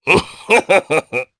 Oddy-Vox-Laugh_jp.wav